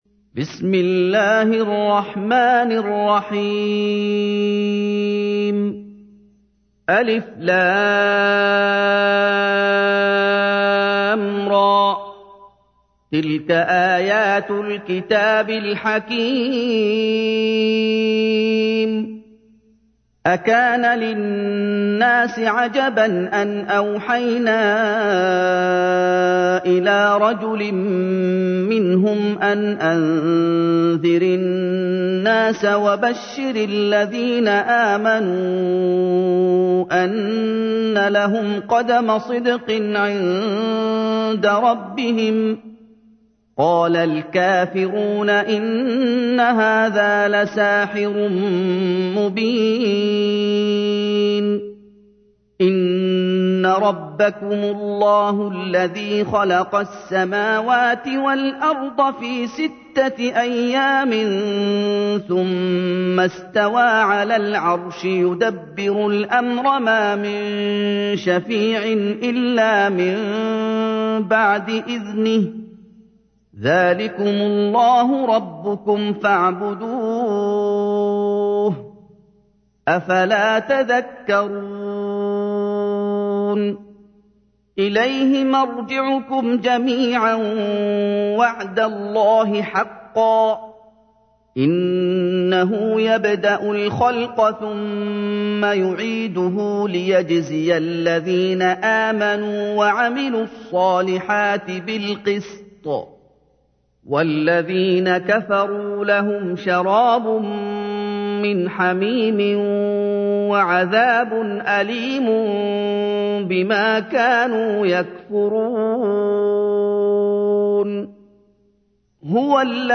تحميل : 10. سورة يونس / القارئ محمد أيوب / القرآن الكريم / موقع يا حسين